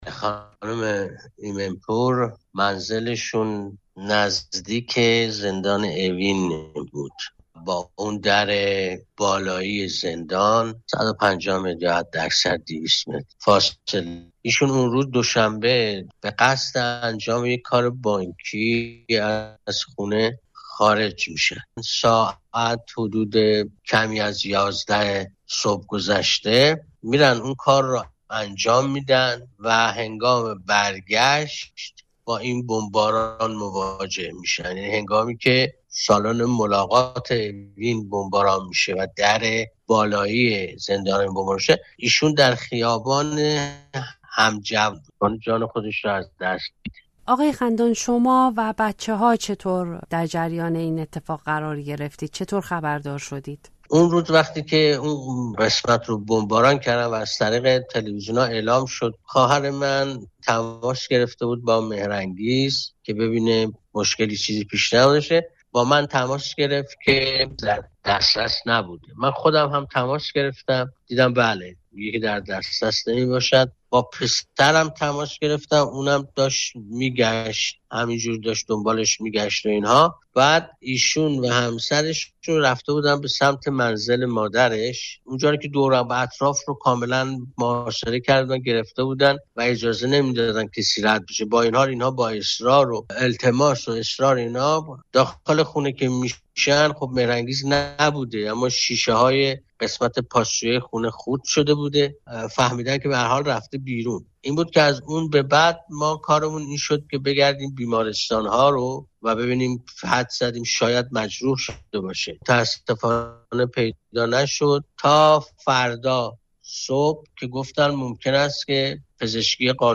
دربارهٔ جزئیات کشته شدن او گفت‌وگو کرده‌ایم.